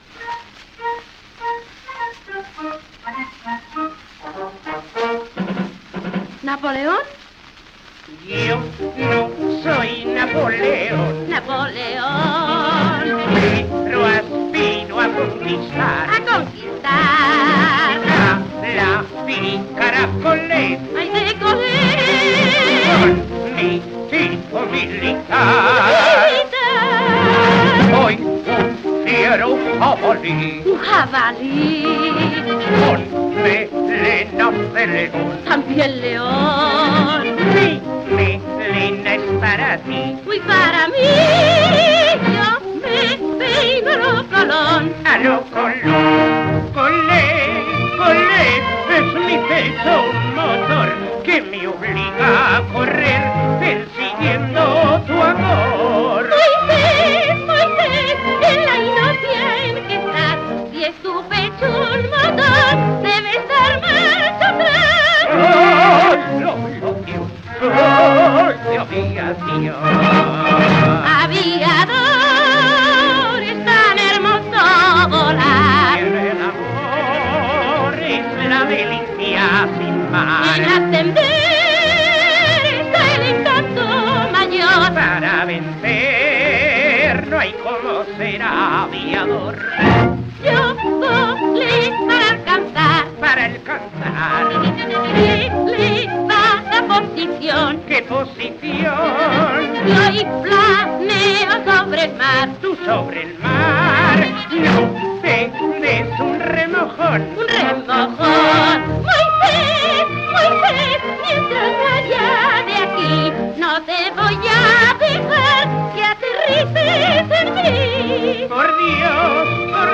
[78 rpm]